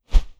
Close Combat Swing Sound 11.wav